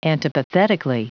Prononciation du mot antipathetically en anglais (fichier audio)
Prononciation du mot : antipathetically